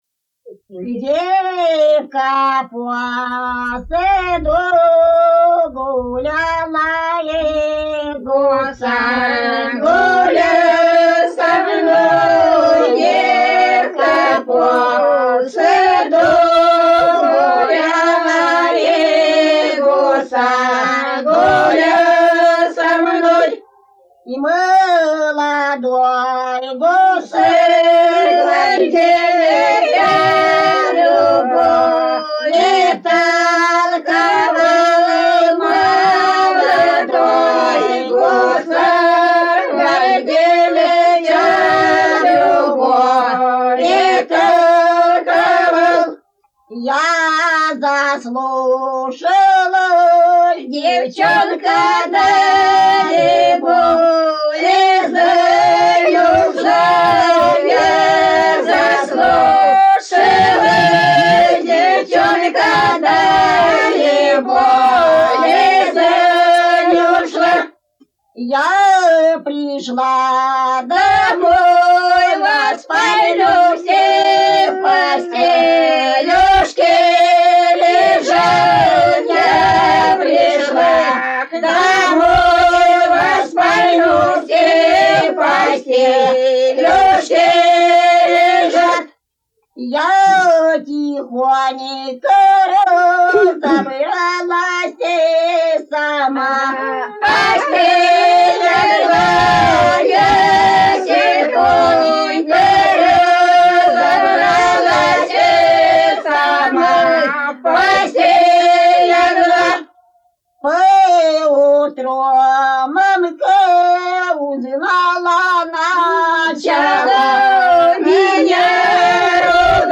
Народные песни Касимовского района Рязанской области «Девка по саду гуляла», лирическая.